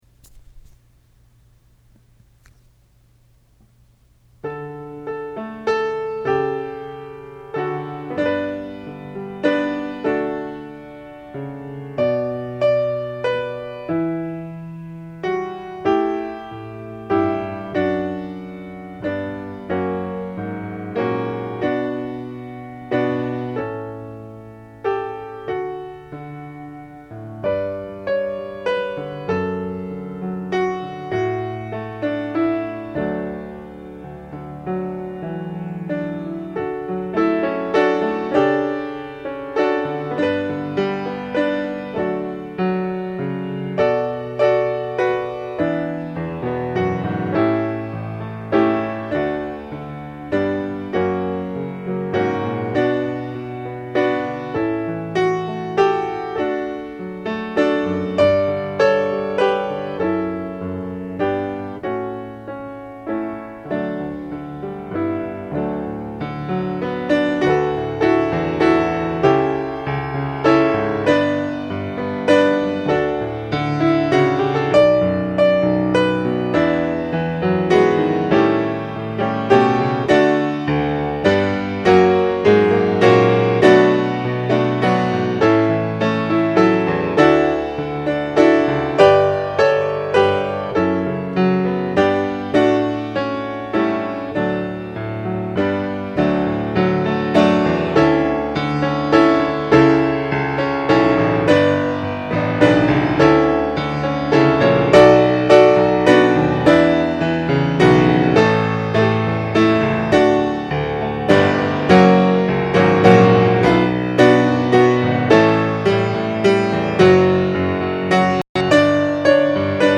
Watchman Nee Hymn
I recorded this on my iPhone in one sitting without looking at the number of stanzas, so please forgive the fact that I don’t play the whole thing through.